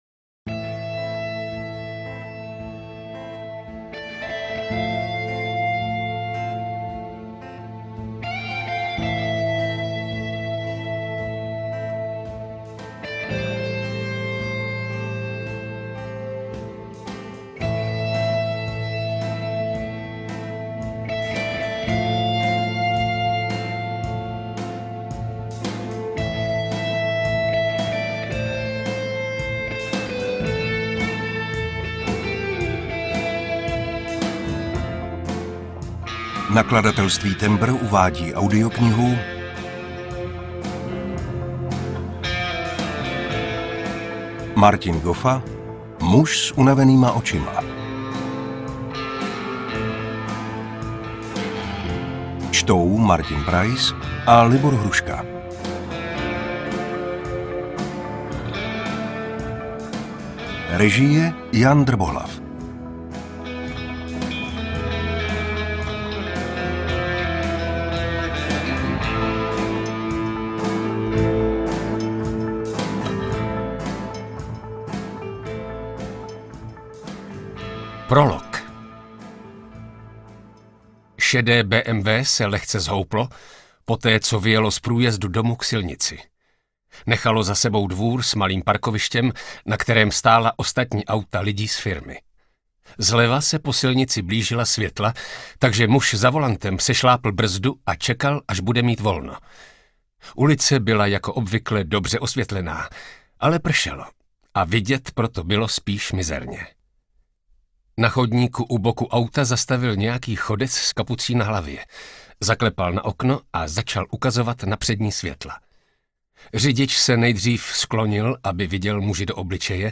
UKÁZKA Z KNIHY
audiokniha_muz_s_unavenyma_ocima_ukazka.mp3